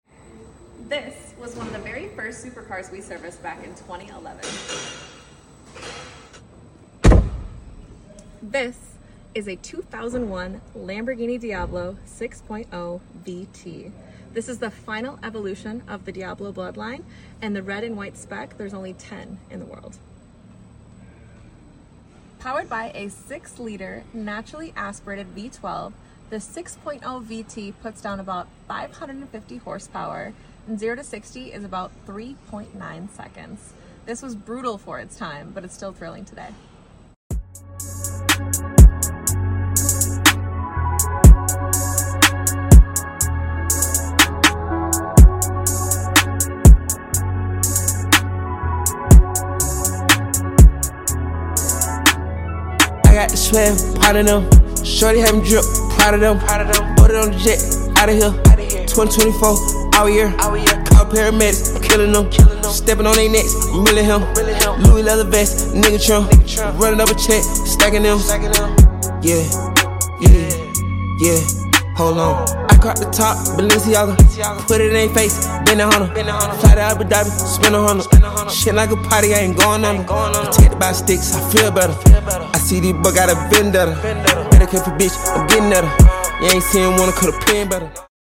550 hp, 6.0L V12, gated 5-speed